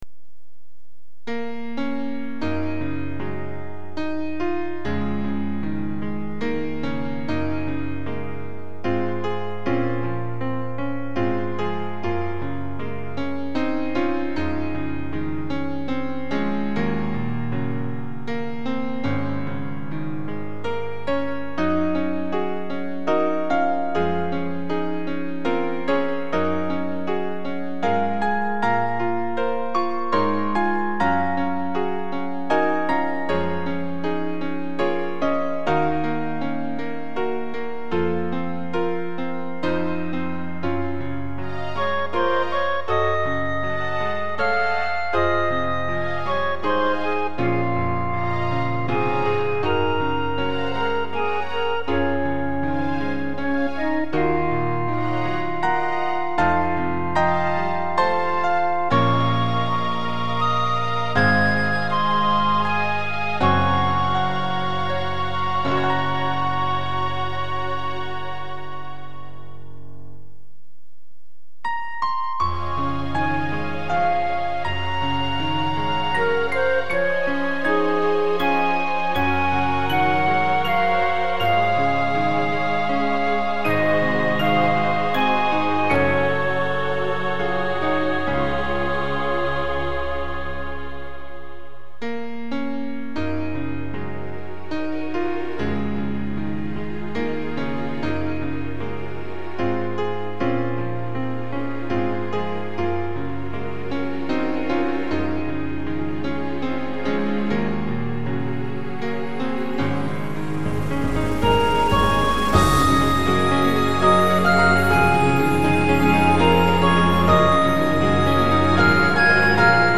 これから きっと 共に歩むことも 触れることもない 向き合うことすらもない そう、それでもね 私は、あなたを応援します 私は、あなたの進む道が好きです 私は、あなたが、好きです 解説 実らぬ片想いへの、告白と終止符を思って。 「暖かさ」と「哀しみ」という、相反する局面を同時に詠う、 当サイトの看板曲。